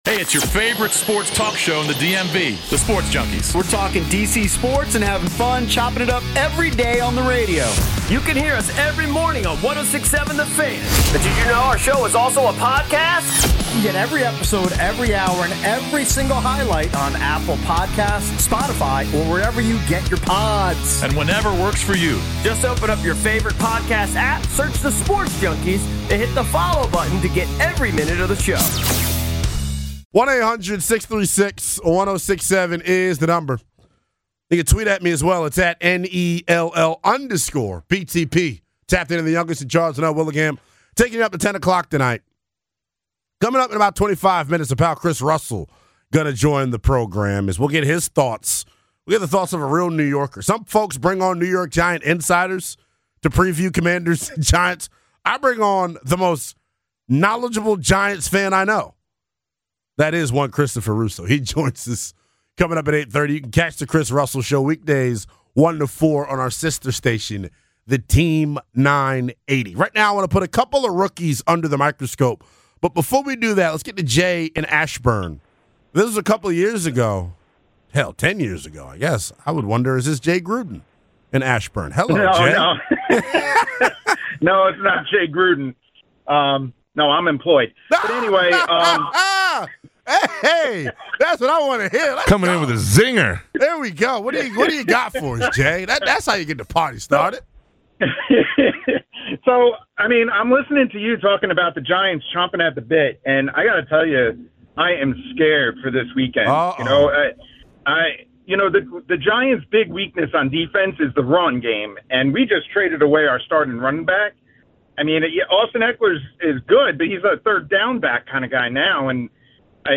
Callers discuss the match up with the Giants in week 1